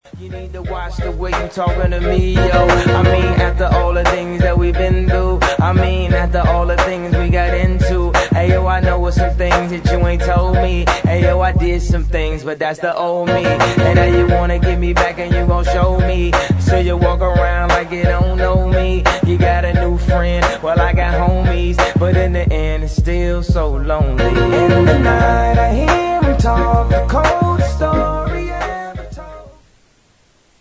• R&B Ringtones